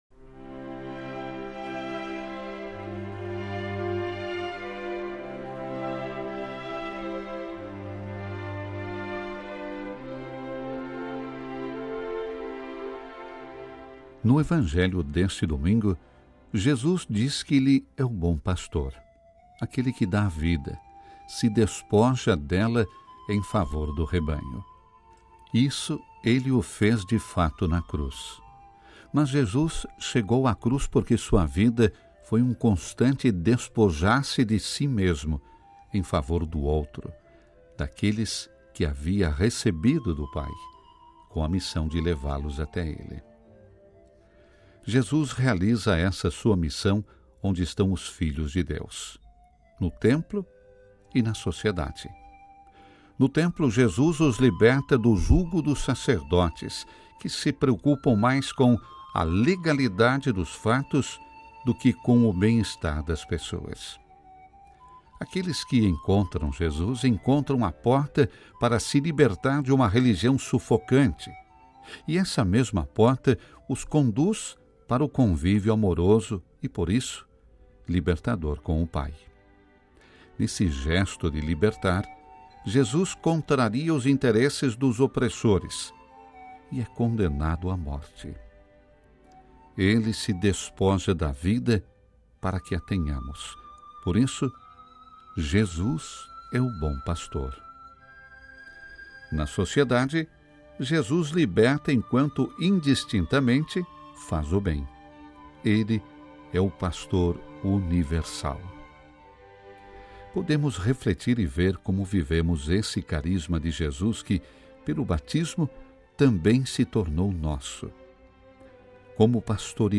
Reflexão para o IV Domingo da Páscoa